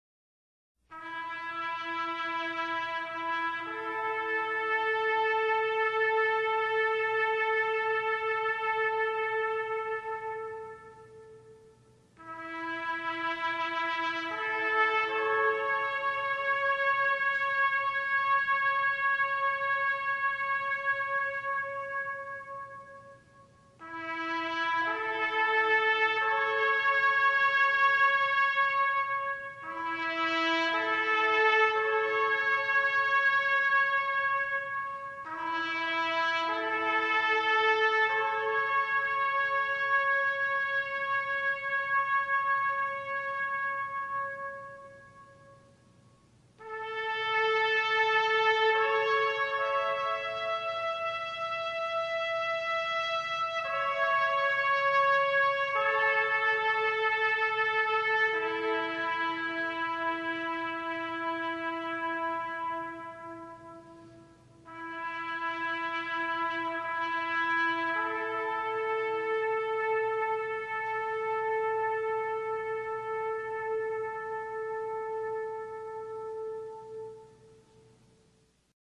Military-Taps.mp3